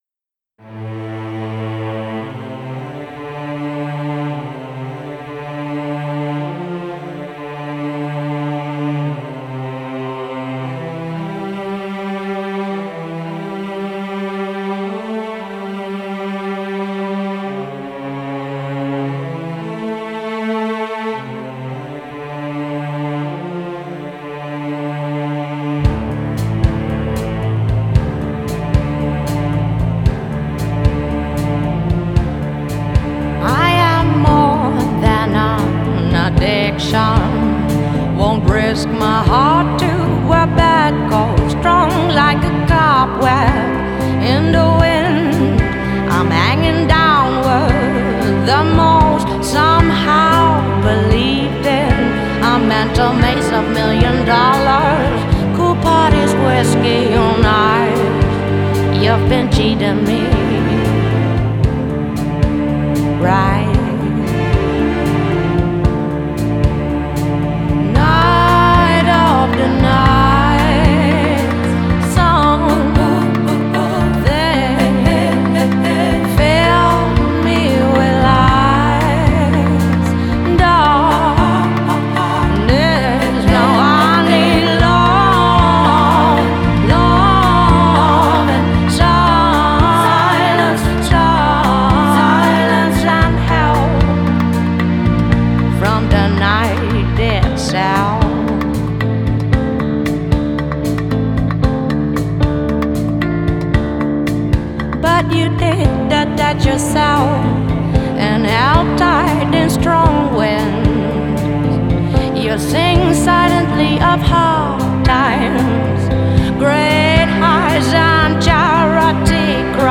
Genre: Indie Pop, Soul, Jazz